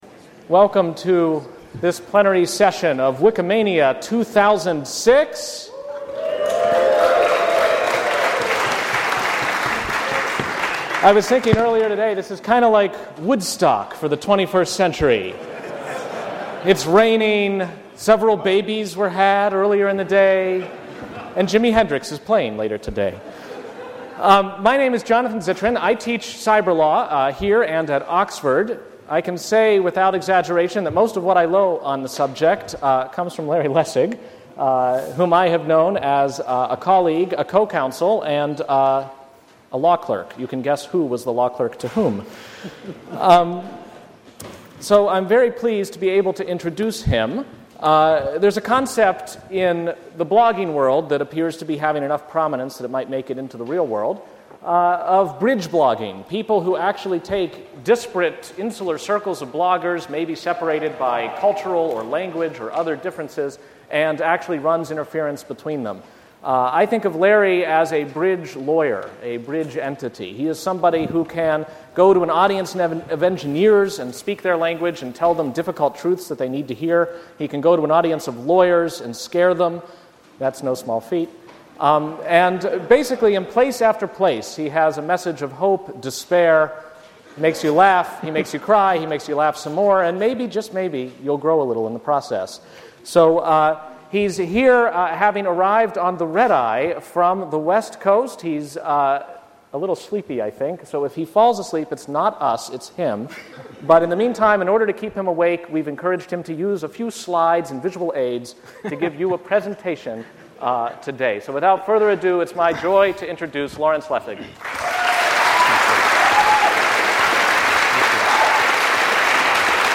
In the plenary session of Wikimania 2006 Lawrence Lessig explains his notion of the difference between “read-only” and “read-write” cultures. Subsequently he addresses Creative Commons and the above mentioned idea of legal code as foundation – as the “plumbing” of free culture.